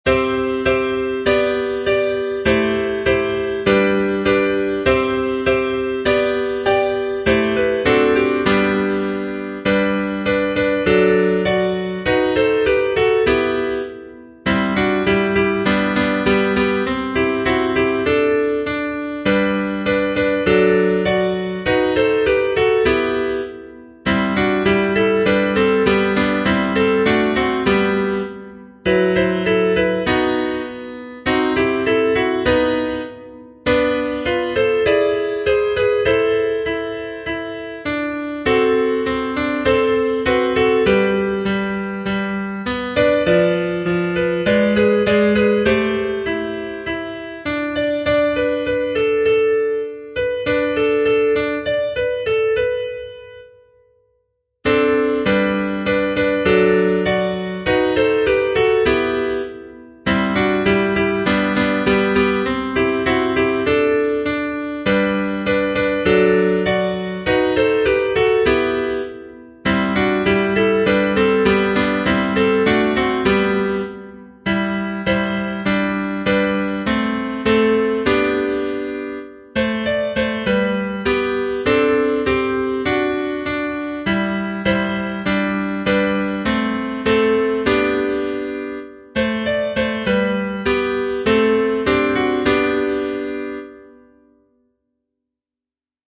ハンドベル